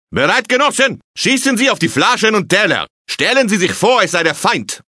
Russischer Trainingskommissar: